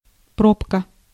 Ääntäminen
IPA: [bu.ʃɔ̃]